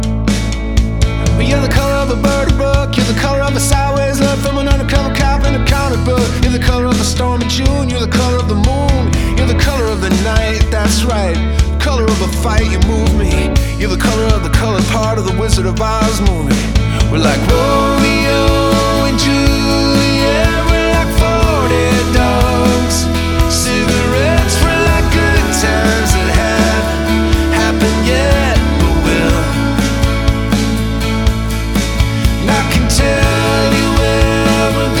# Americana